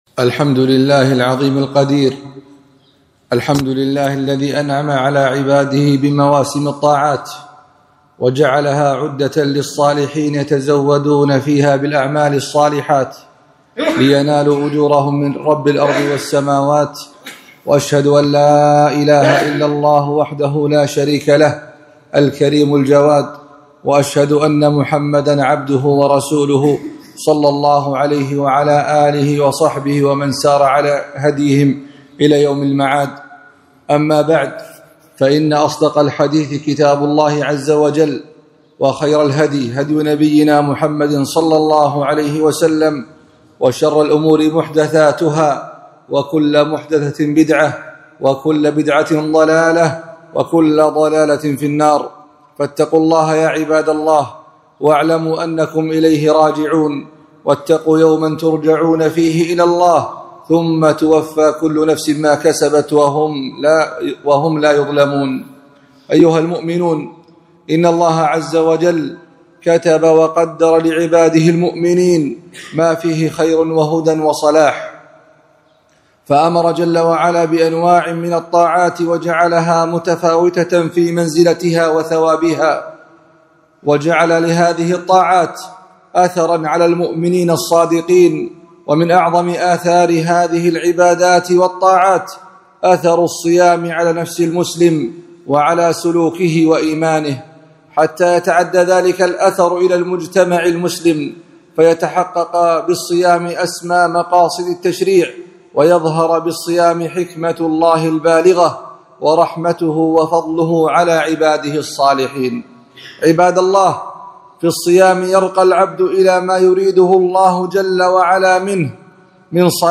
خطبة - مقاصد الصوم